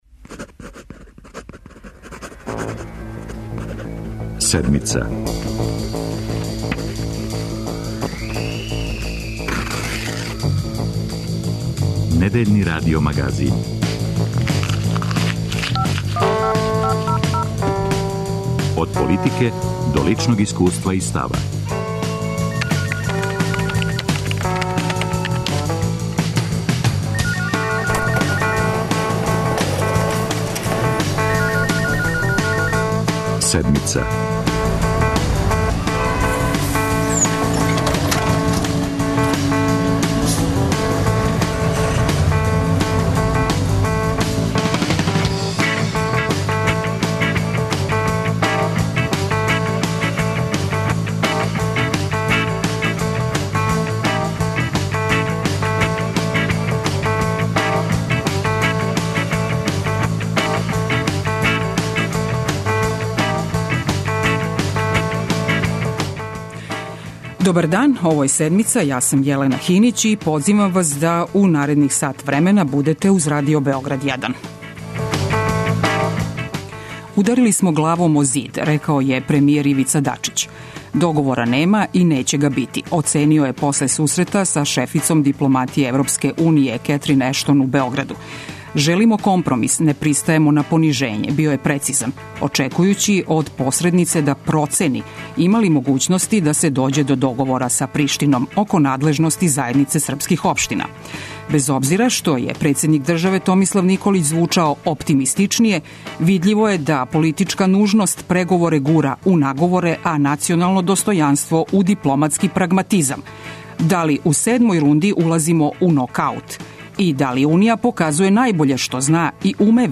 Наш саговорник је заменик директора владине Канцеларије за КиМ Крстимир Пантић.